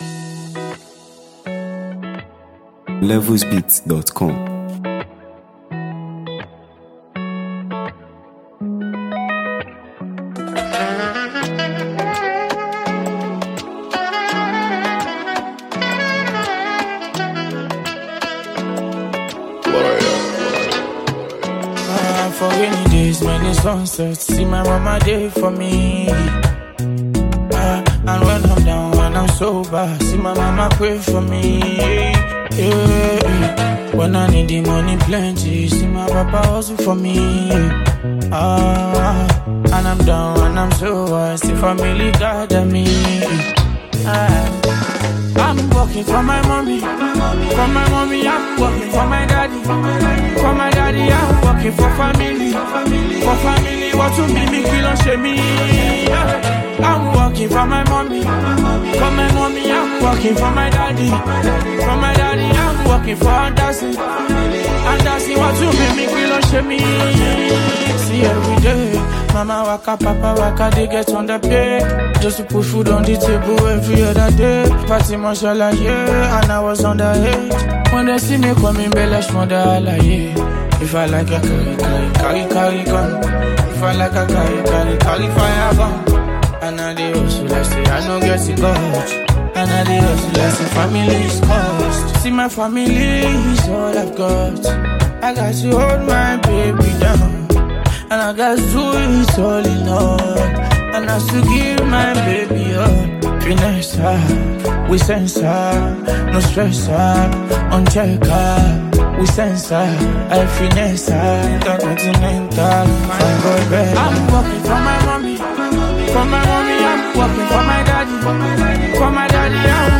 Well-known Nigerian street-pop sensation and songwriter
With its strong message and calming vibe